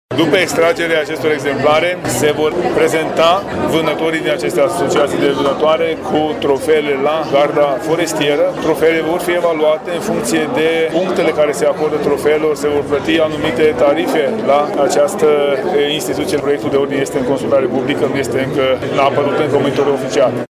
Ministrul Mediului a mai explicat că, potrivit ordinului aflat în dezbatere, după împușcarea urșilor, trofeele vor fi colectate, iar asociațiile de vânătoare vor primi o sumă de bani pentru fiecare trofeu predat: